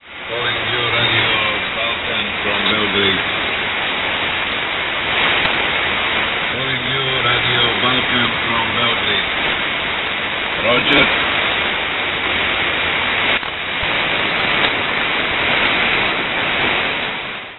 Pirate radio from Serbia received in Finland
Serbian pirates on Medium Wave
They are mostly QSO-stations with no music programmes.